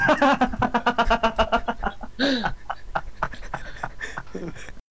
Crazy boi laf